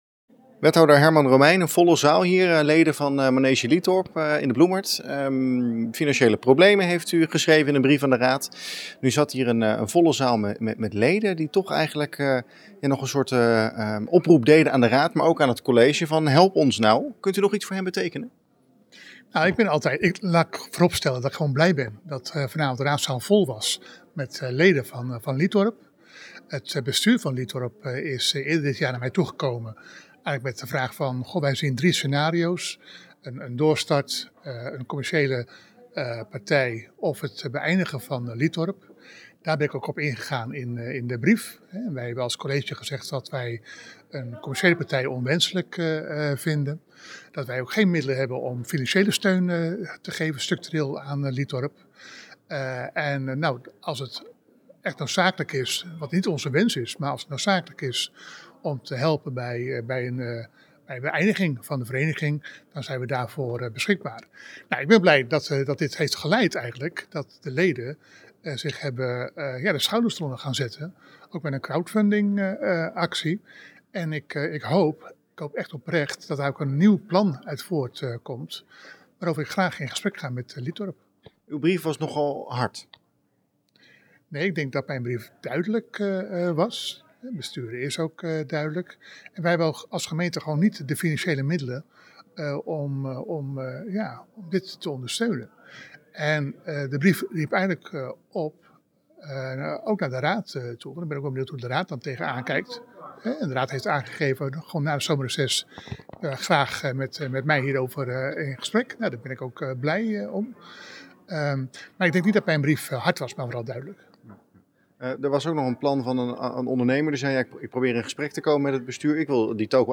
AUDIO: Wethouder Herman Romeijn over Liethorp.